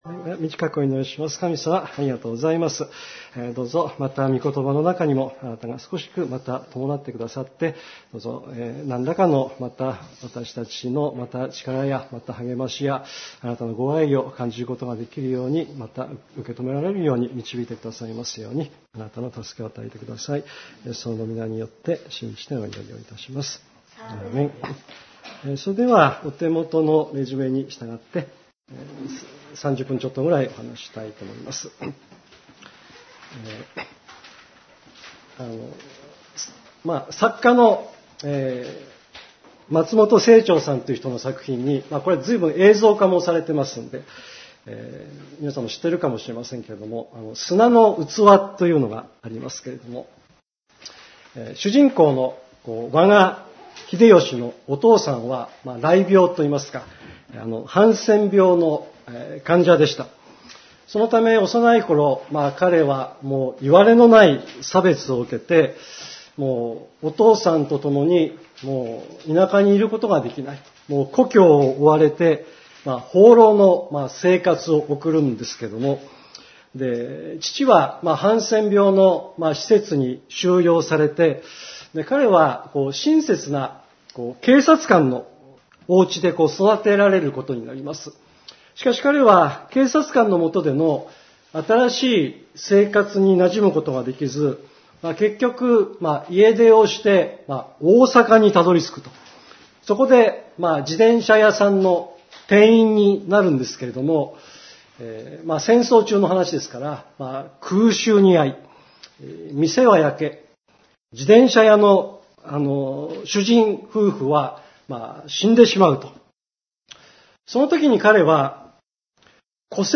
2018年11月４日 礼拝メッセージ 「キリストの系図」 – 基督聖協団目黒教会